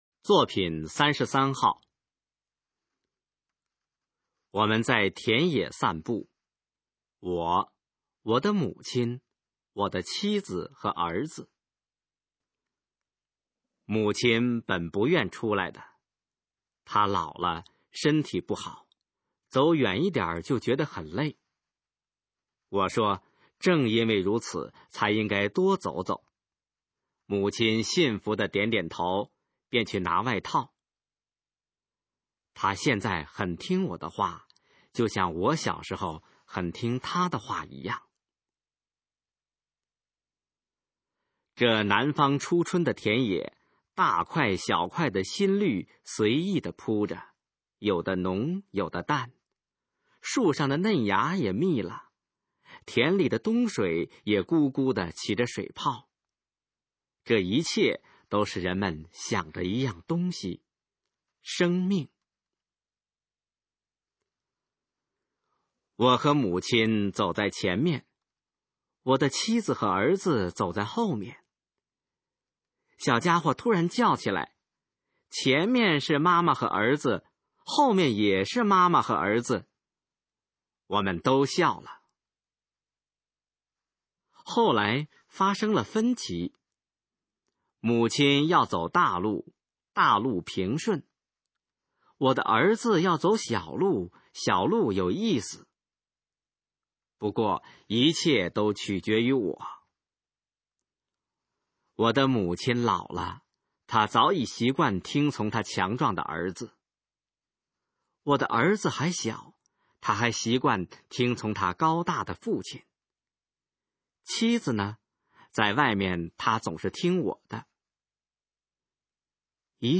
《散步》示范朗读_水平测试（等级考试）用60篇朗读作品范读